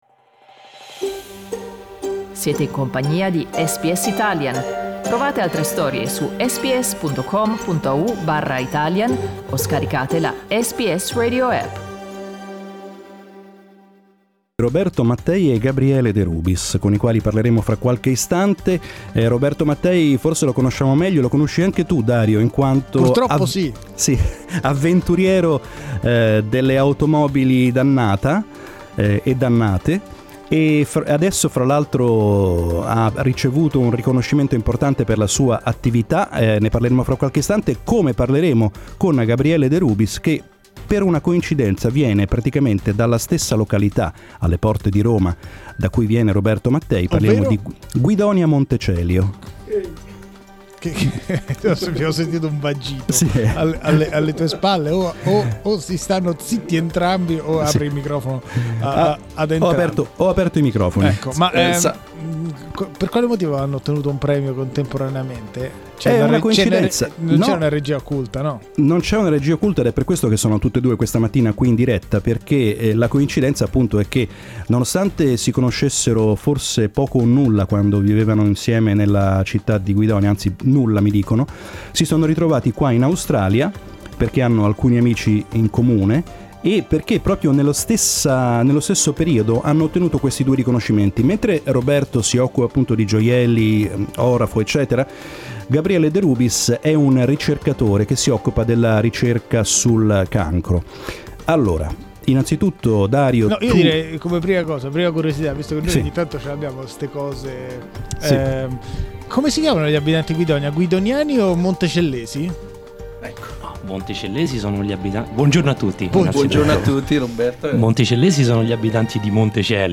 Today they were our live guests to share their success stories.